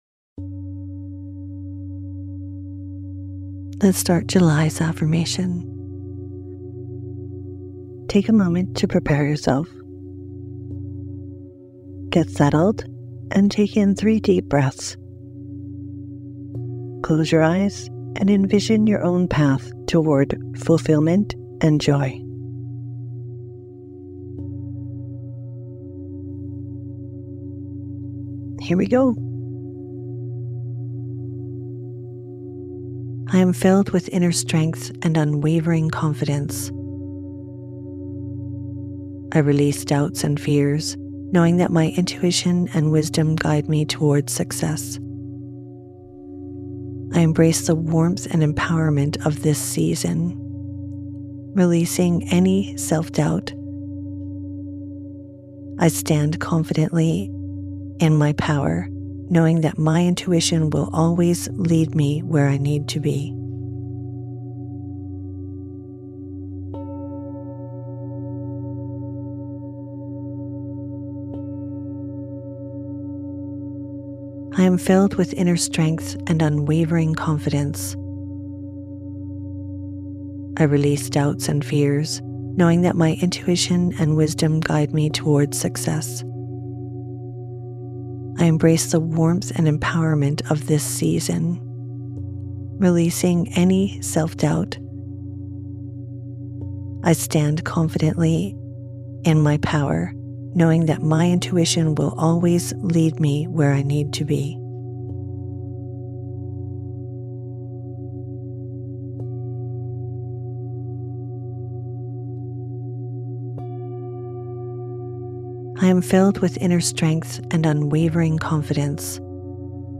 A gentle reminder to bloom in your own time. This seasonal affirmation invites you to embrace the warmth, growth, and vibrant energy of summer.